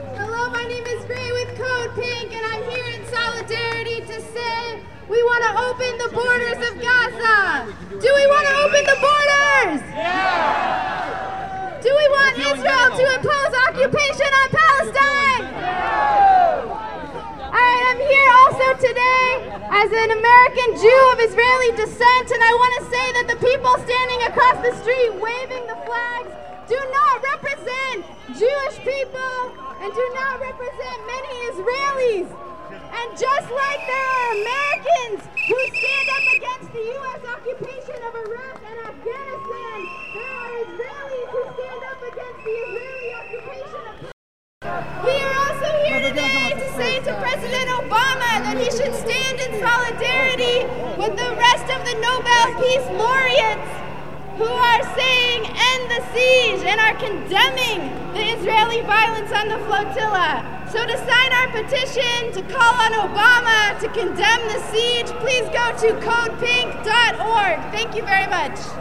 Two speech excerpts and three interviews from the 6/4/10 protest at the Israeli consulate
Unfortunately the jostling made it impossible to get good sound quality for the "bullhorn" speakers (and, in one case, even to get his name).